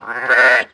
pecora_sheep08.wav